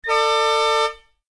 KART_raceStart2.ogg